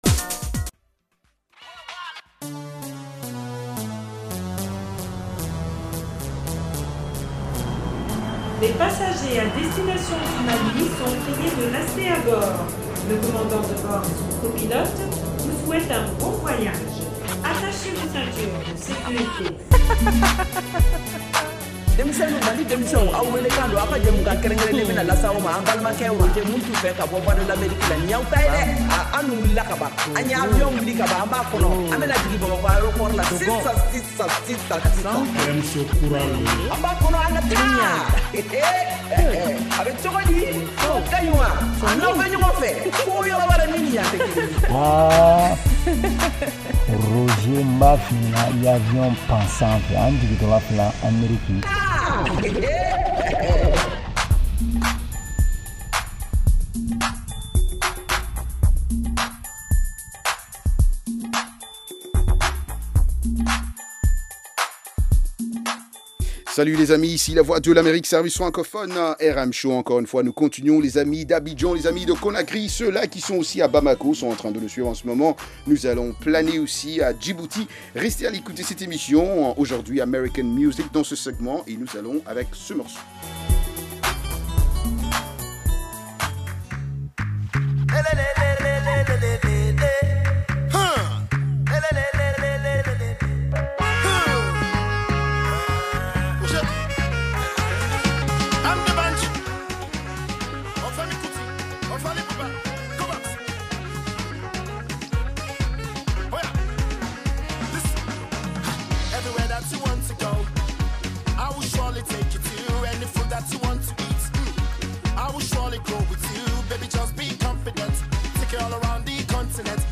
Emission quotidienne de musique et d’entretien avec les auditeurs.